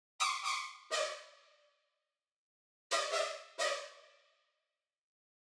Pitch Synth.wav